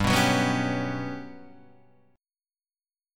GM7b5 chord {3 2 4 4 2 3} chord